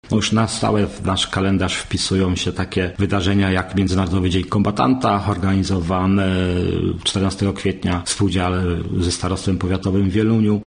– mówił Zbigniew Dąbrowski, wójt gminy Mokrsko.